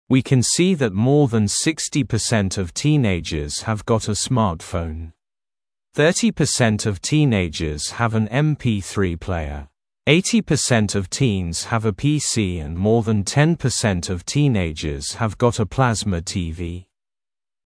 Произношение:
[Уи кэн си зэт мо зэн сиксти пёрсент оф тинэйджеры хэв гот э смартфоун. Фёти пёрсент оф тинэйджеры хэв эн эм-пи-фри плэйэр. Эйти пёрсент оф тинз хэв э пи-си энд мор зэн тен пёрсент оф тинэйджеры хэв гот э плэзма ти-ви].